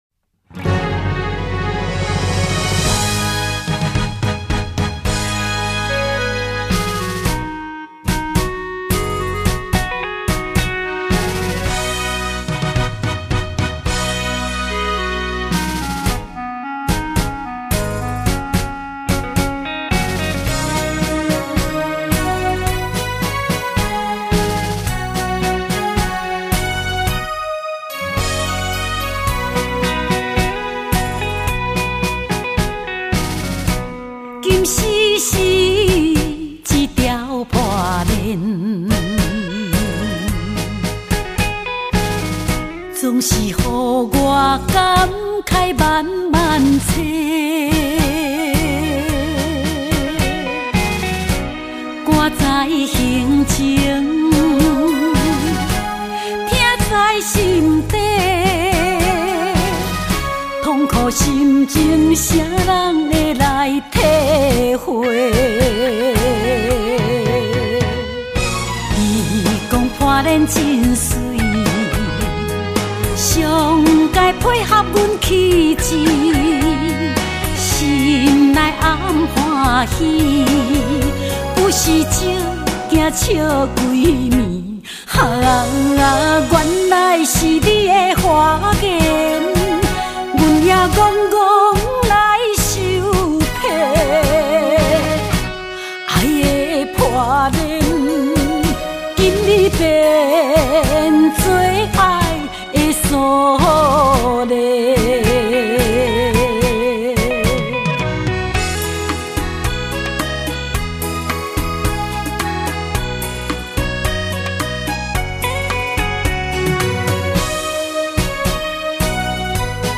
娥式唱腔 风靡歌坛 台湾最美丽的声音